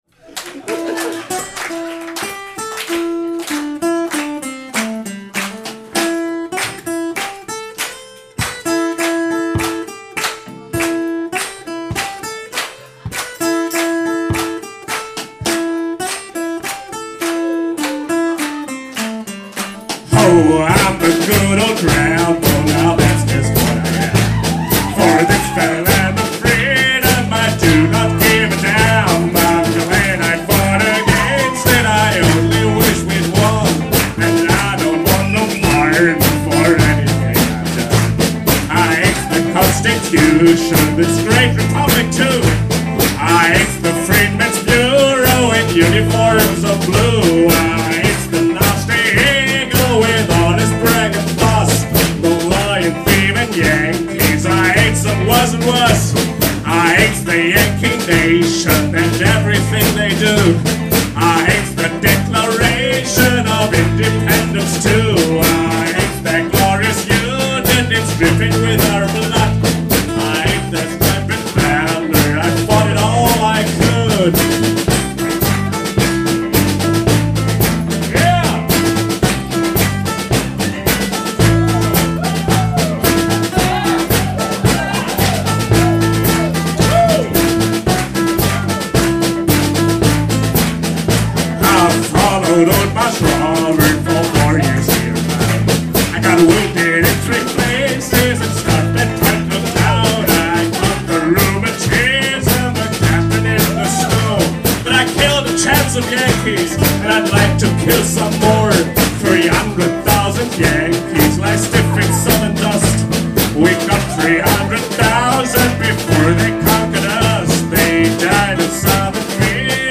(live&mono)